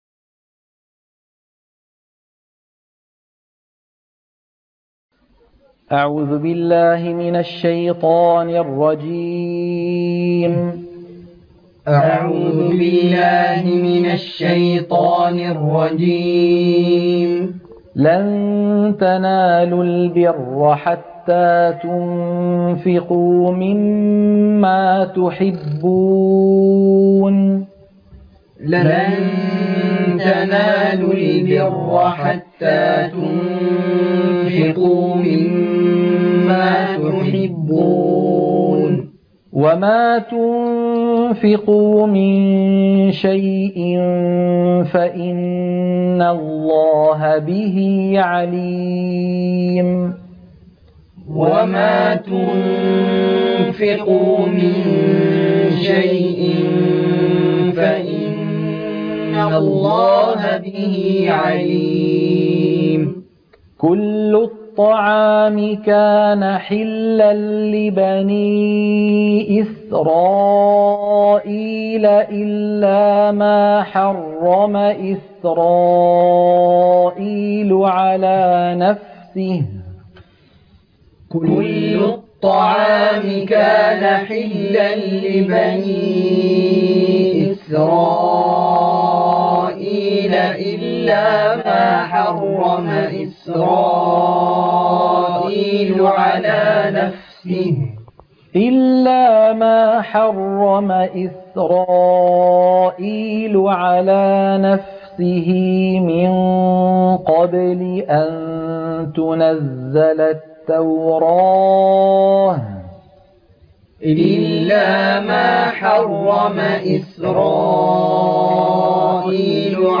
عنوان المادة تلقين سورة آل عمران - الصفحة 62 التلاوة المنهجية